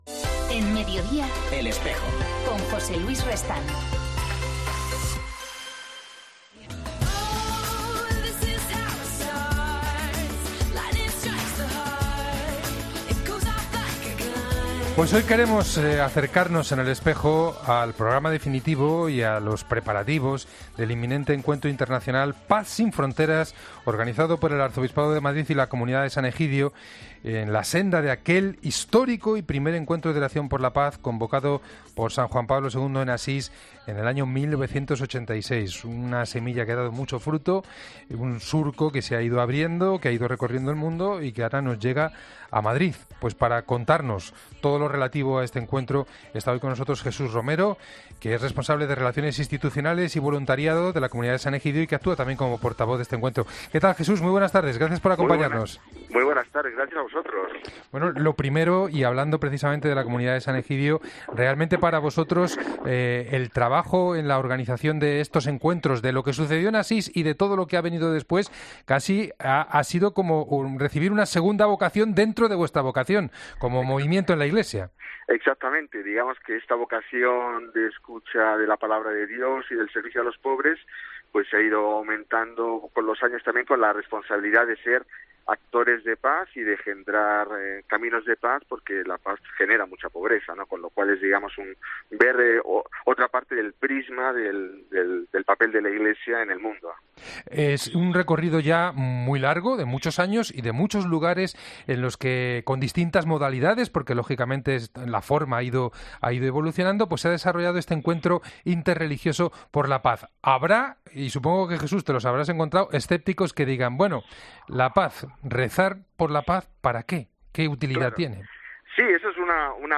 En 'El Espejo' hemos hablado con el sacerdote